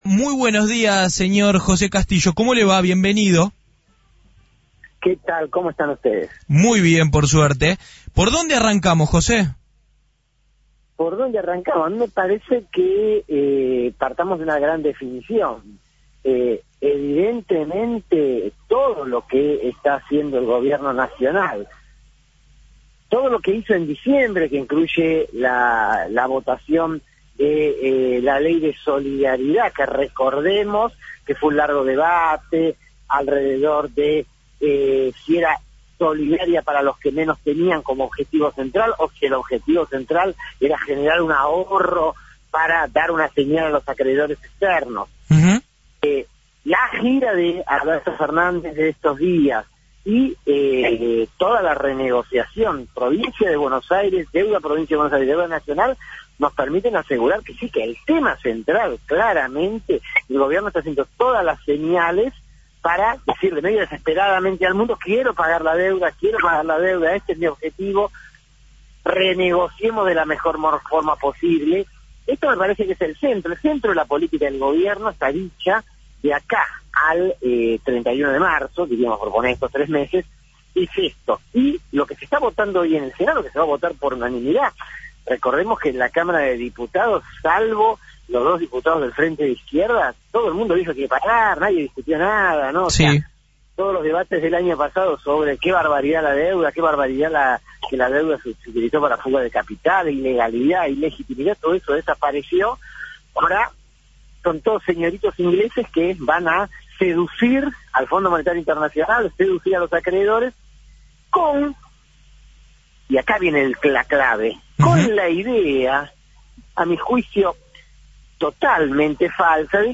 En dialogo con FRECUENCIA ZERO